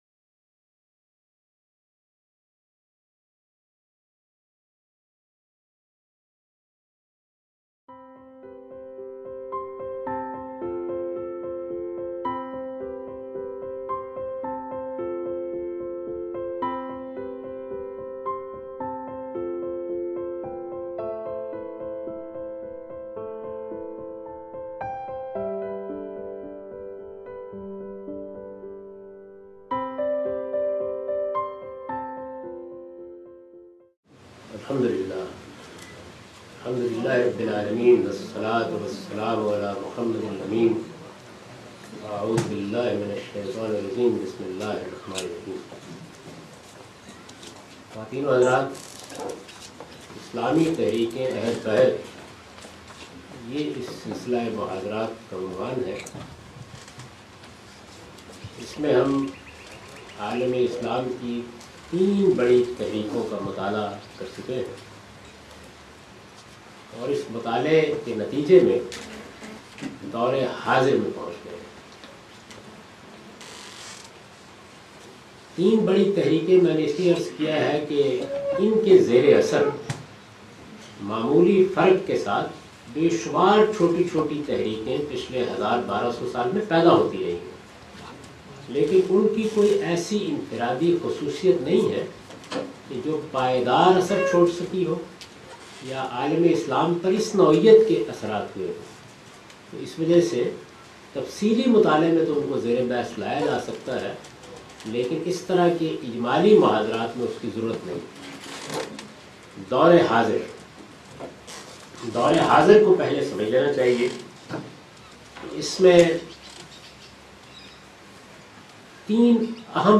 This series of lectures was recorded in Australia in January 2014.